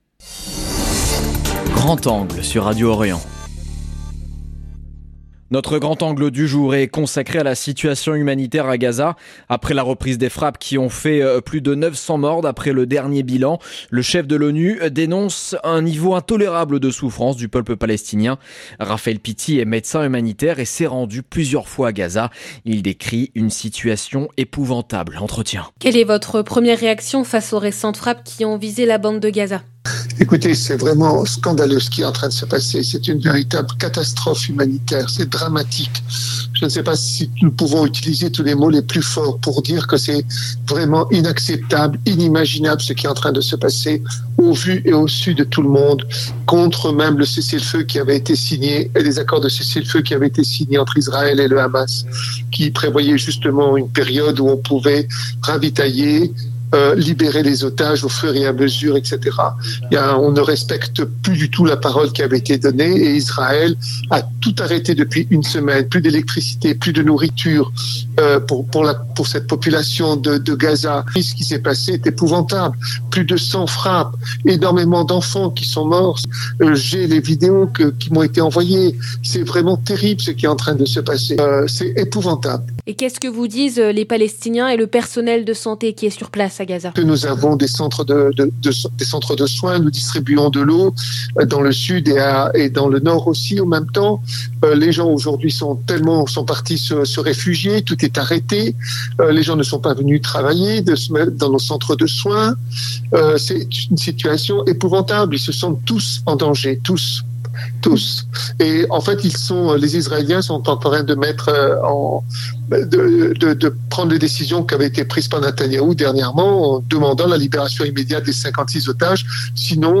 Entretien. 0:00 9 min 49 sec